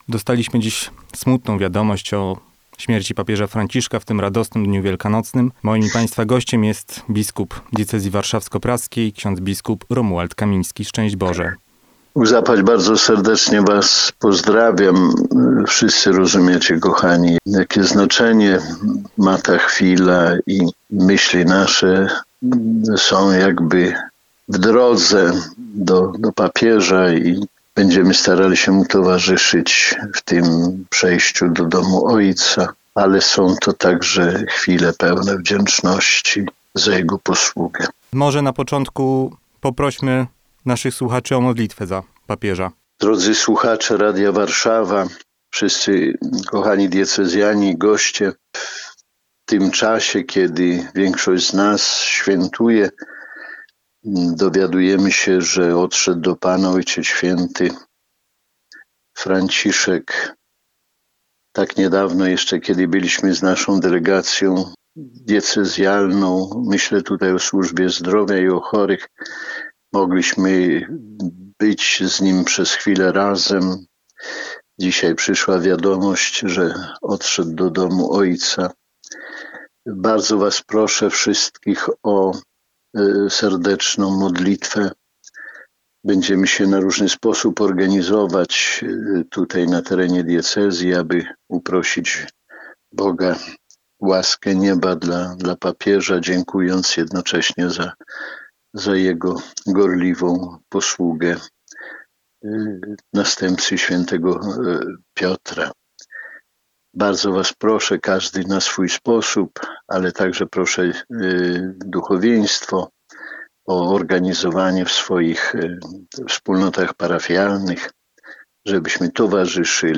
W specjalnej rozmowie na antenie Radia Warszawa biskup diecezji warszawsko-praskiej, ks. bp Romuald Kamiński, podzielił się swoimi refleksjami, wdzięcznością i wezwaniem do modlitwy.
Na antenie biskup Kamiński z wielkim wzruszeniem mówił o duchowym znaczeniu tej chwili:
Rozmowa-bp-Romuald-na-antene.mp3